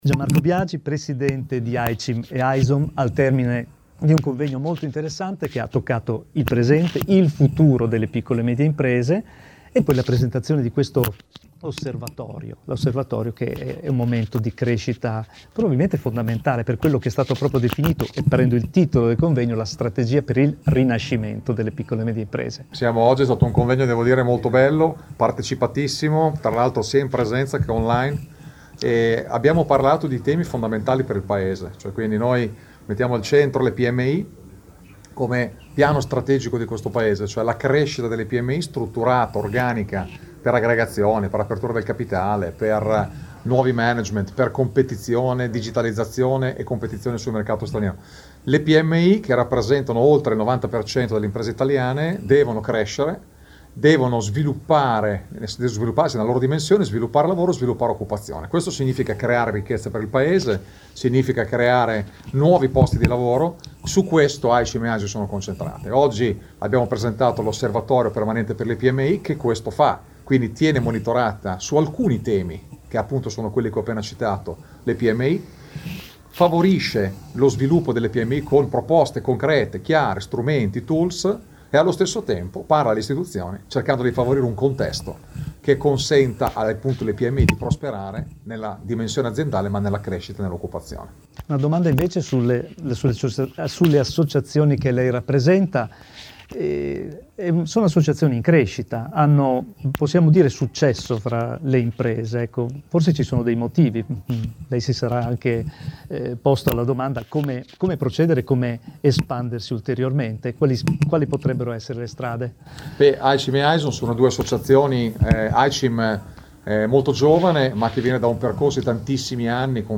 Lieti di essere main sponsor di questo importante evento, “Strategia per il rinascimento delle PMI”, organizzato AICIM e AISOM presso il Grand Hotel Magestic già Baglioni di Bologna.
Intervista a